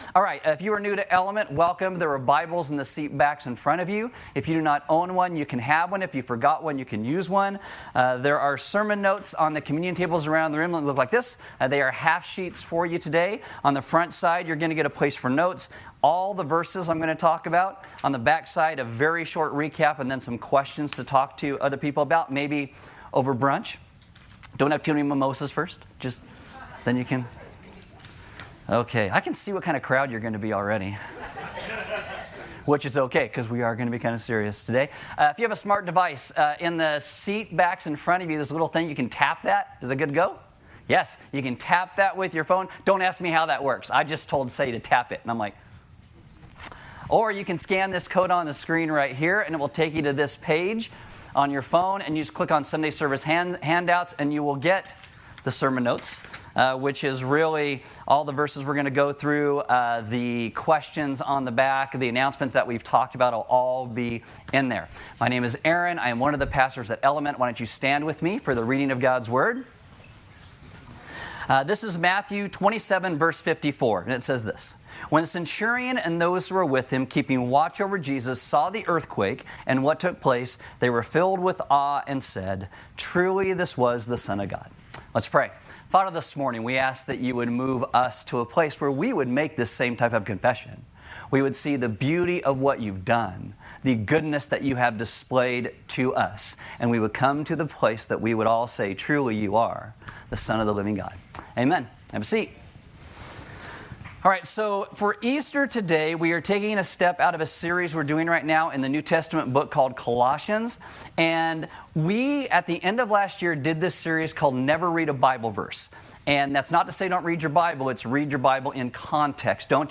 NOTE: We are still working on importing our vast sermon library.